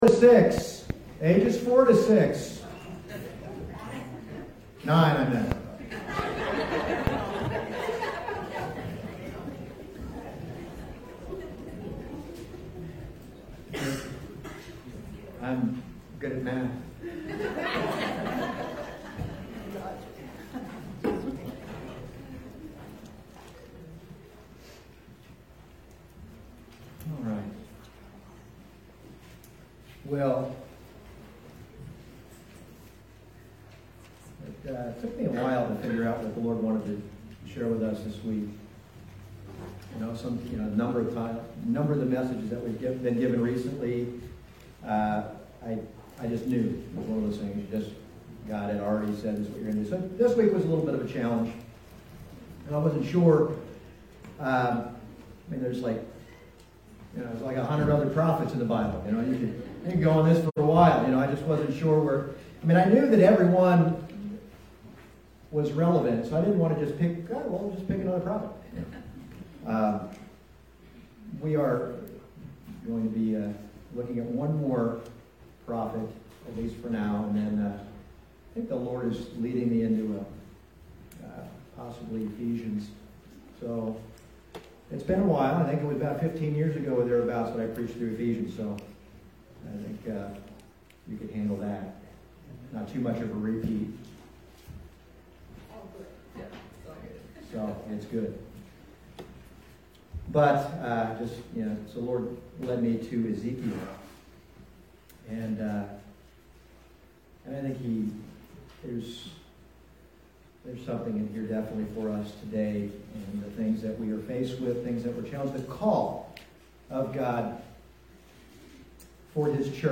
Passage: Ezekiel 22:23-31 Service Type: Sunday Morning